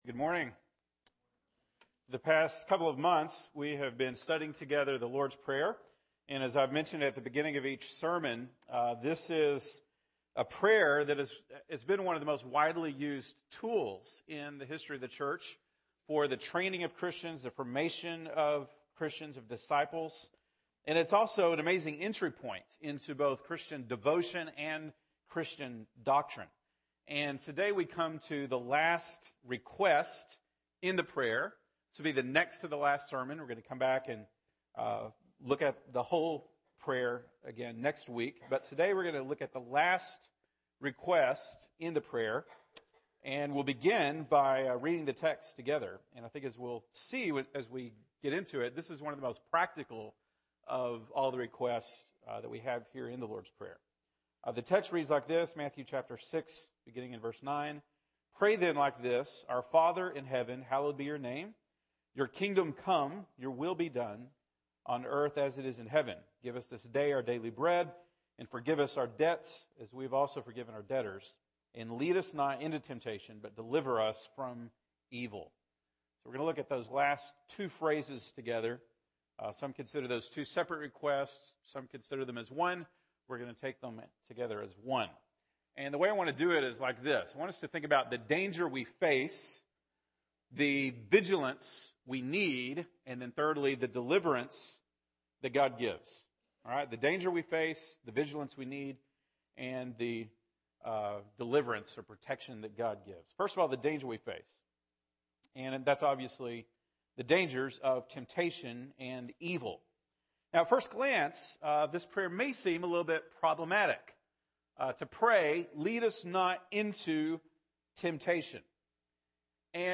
November 16, 2014 (Sunday Morning)